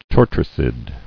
[tor·tri·cid]